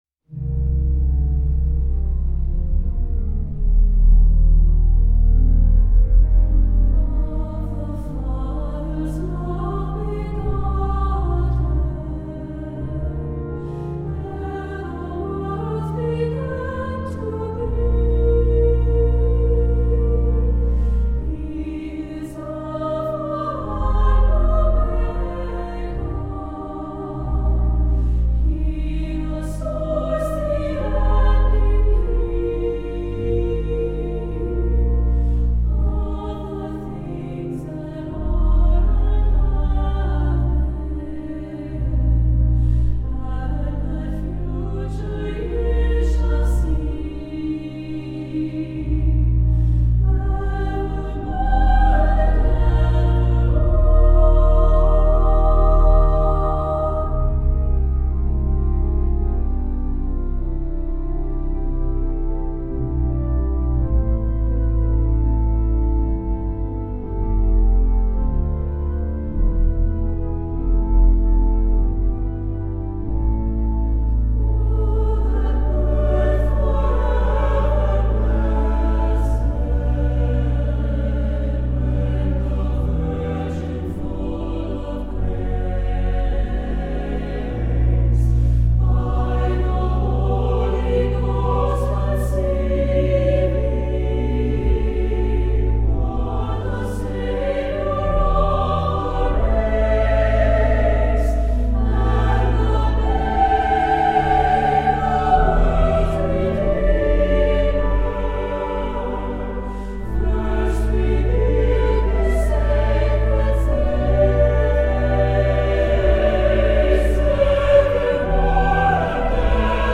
Voicing: SATB and Organ